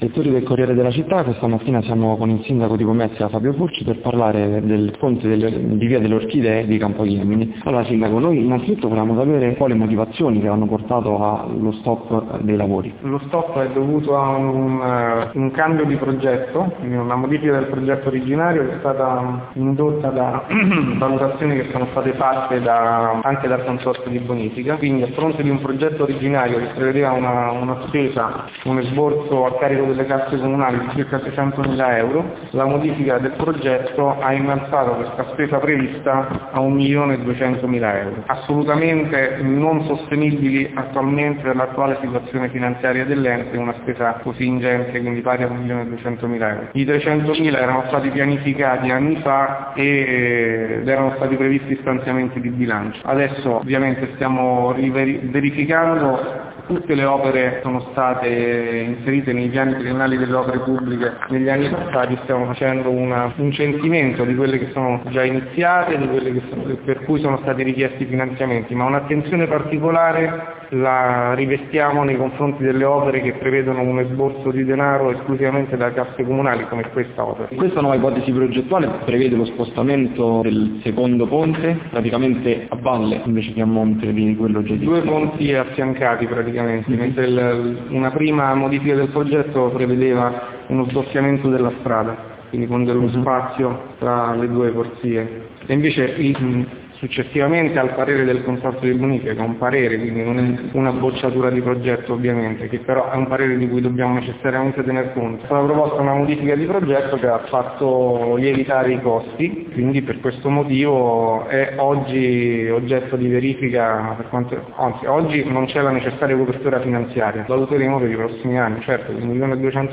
Come anticipato nei giorni scorsi riportiamo anche nel nostro spazio on-line le parole del Primo Cittadino, in versione integrale, registrate ai nostri microfoni.
Intervista-sindaco-edit.mp3